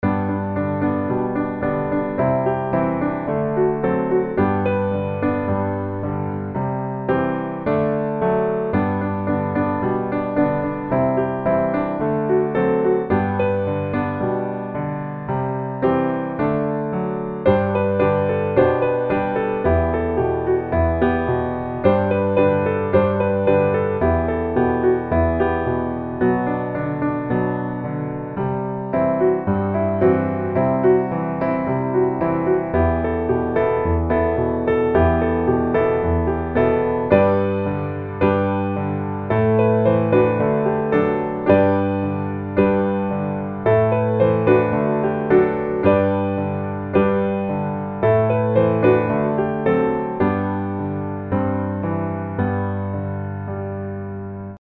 G Majeur
Peculiar Meter.